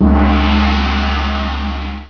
op_gong.wav